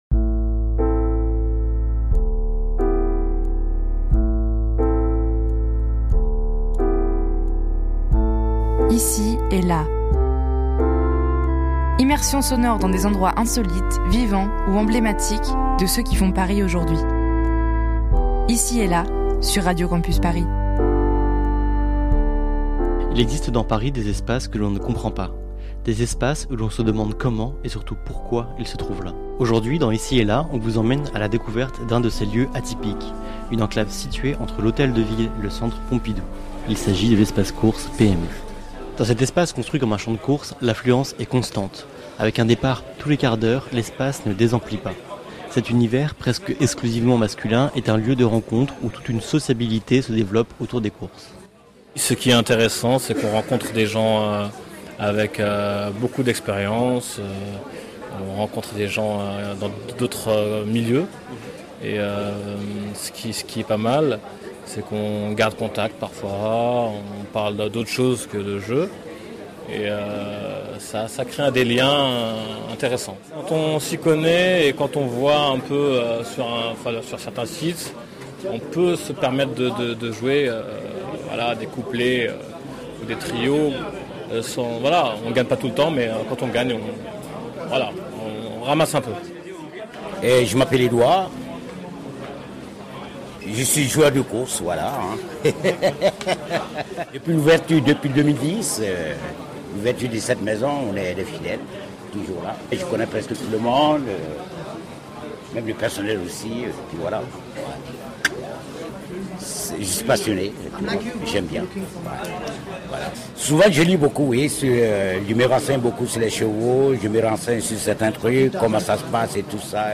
Pour cette première diffusion de Ici et Là, nous inaugurons notre série de portraits de lieux par une visite de l'espace course PMU situé à l'angle rue du Renard, rue Saint Merri.